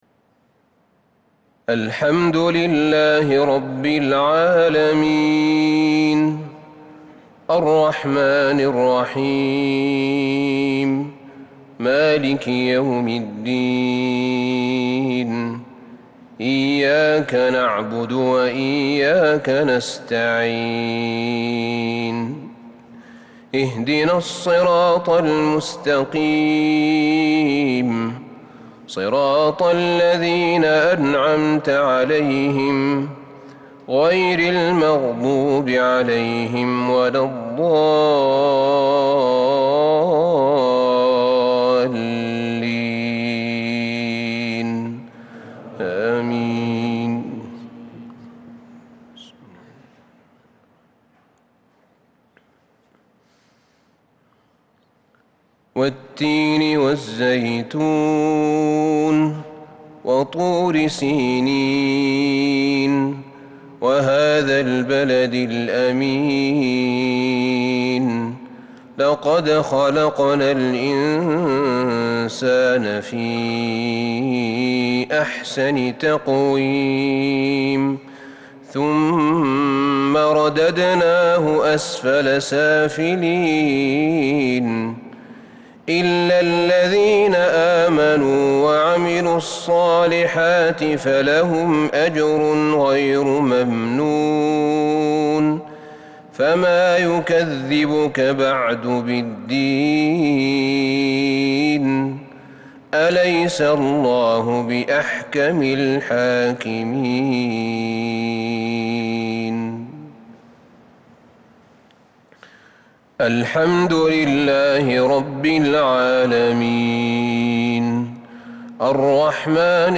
مغرب الثلاثاء 27 شوال 1442هـ سورتي التين والكوثر | Maghrib prayer from Surat Al-Teen and Al-Kawthar 8-6-2021 > 1442 🕌 > الفروض - تلاوات الحرمين